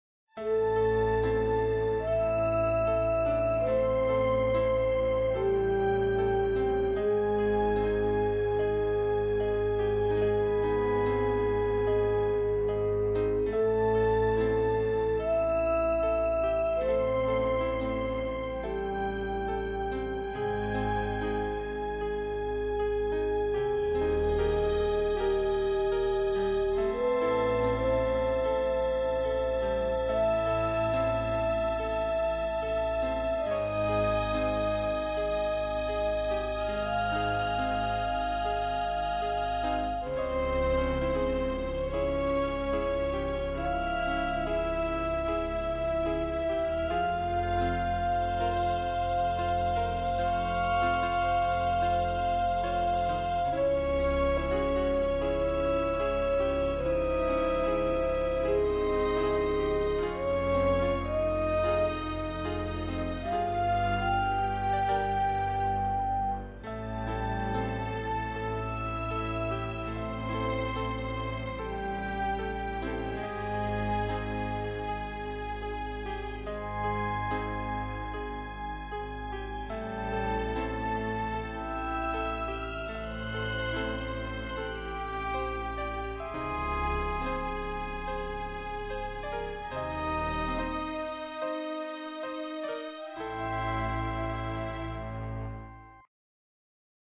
A strange spooky tune I made